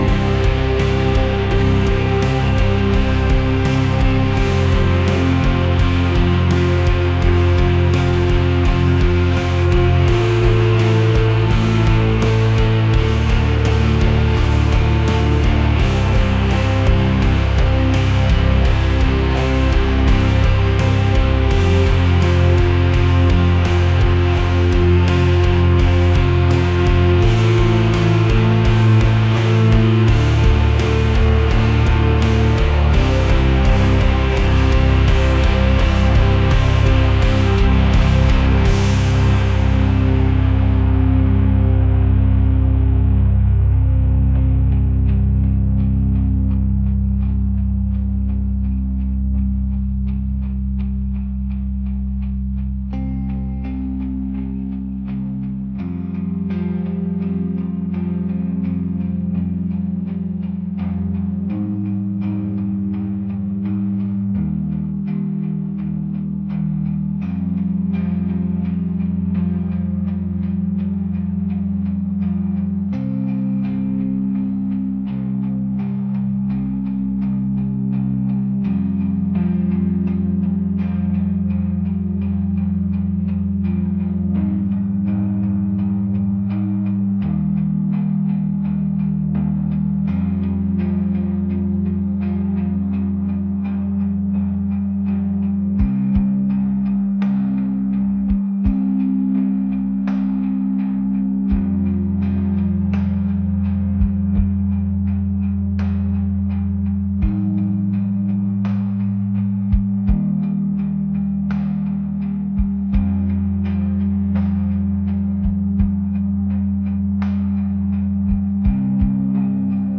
rock | atmospheric